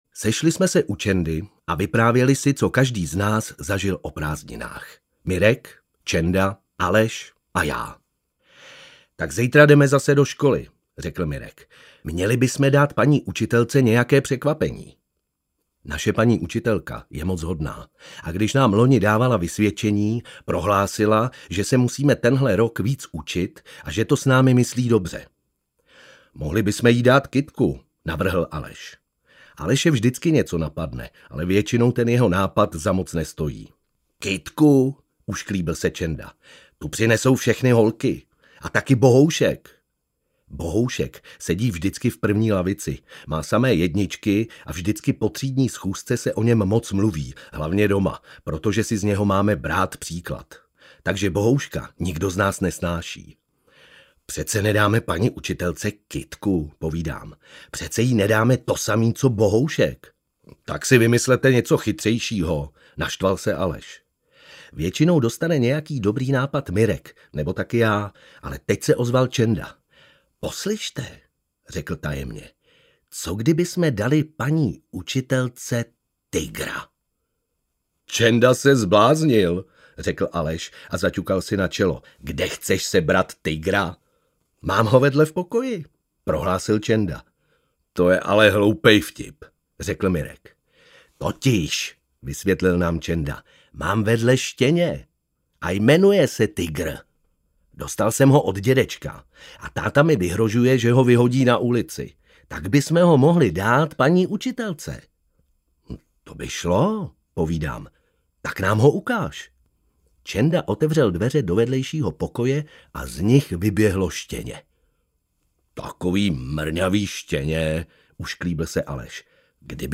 Obálka audioknihy Bořík & spol.